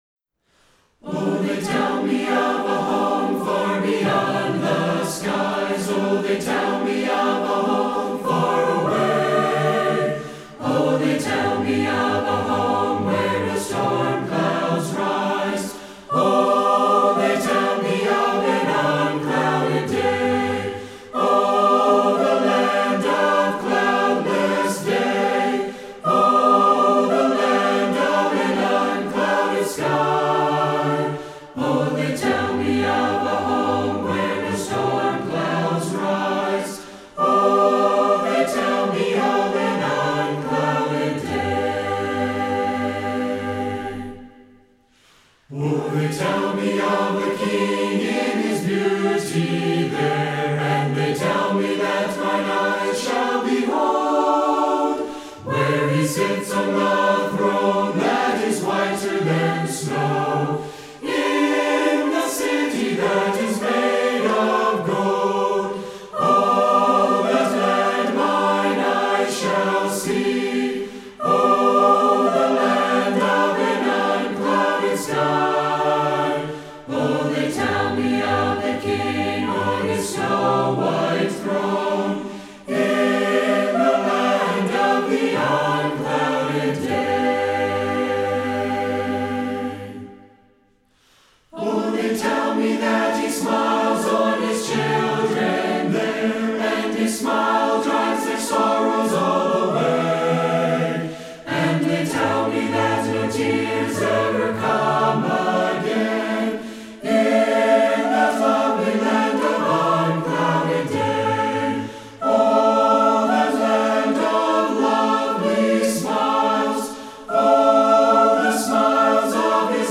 Lebanon County Youth Chorus